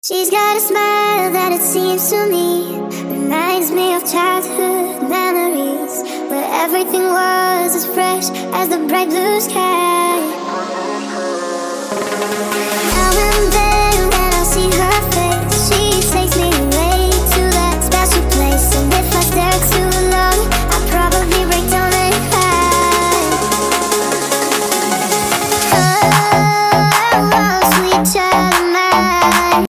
feel good dance classic anthem tunes